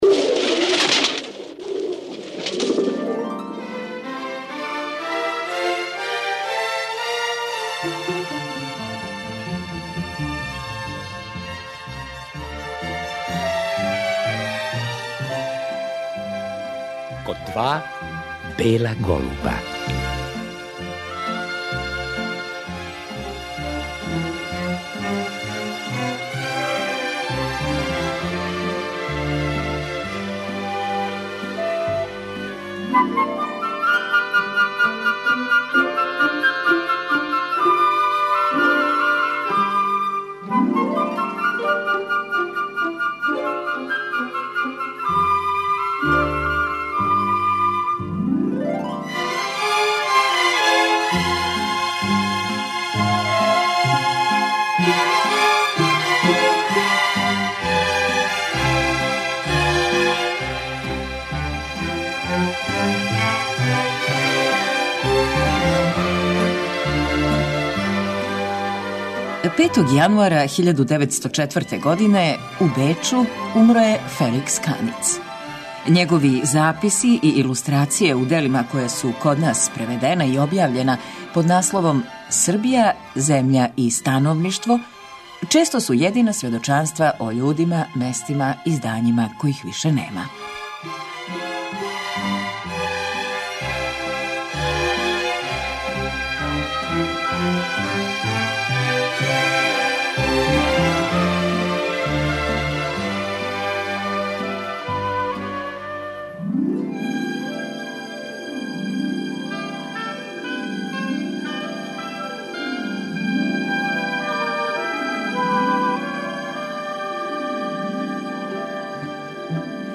Године 2010. поводом изложбе „С Каницом по Србији" одржан је међународни скуп Феликс Каниц, живот и дело.